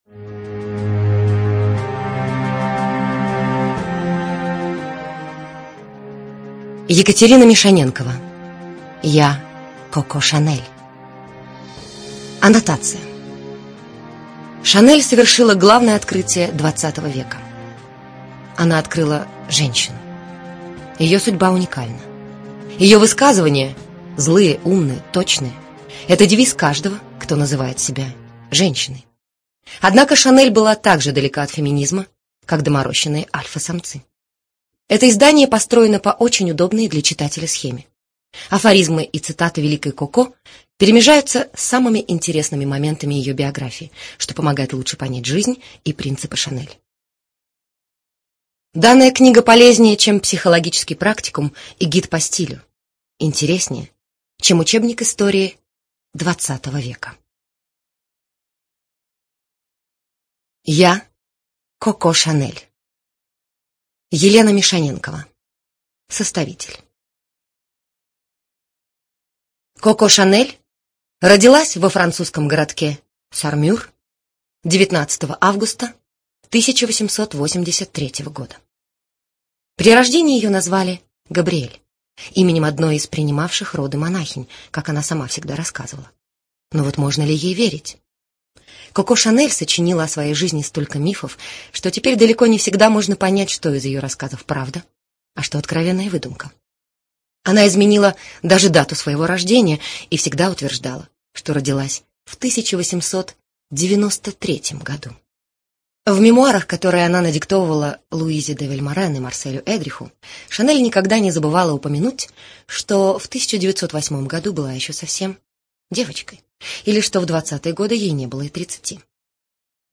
ЖанрБиографии и мемуары, Афоризмы